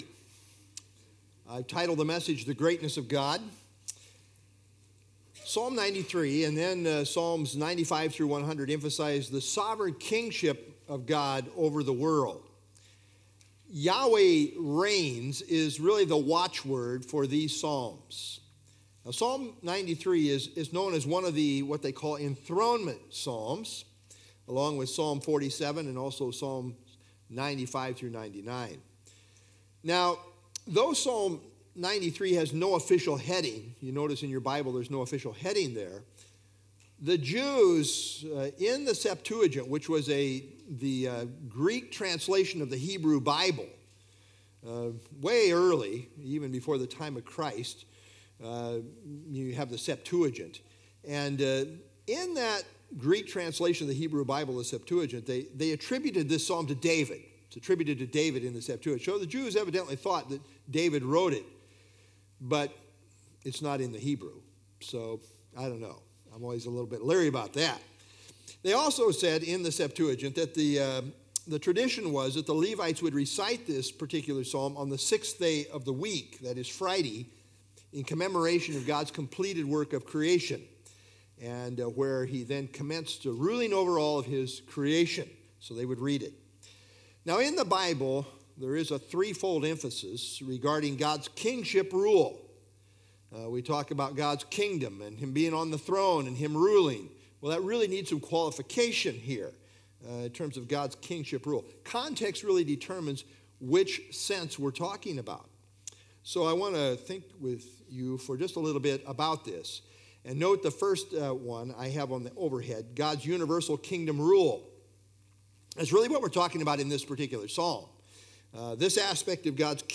( Sunday Evening )